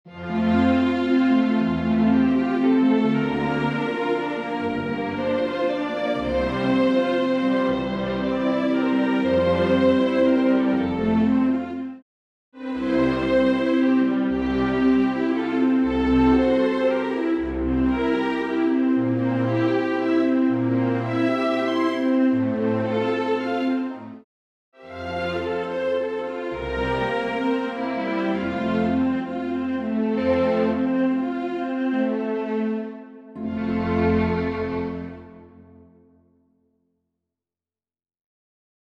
chorus cut
full orchestral accompaniment